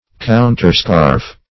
(koun`t[~e]r*sk[aum]rf`)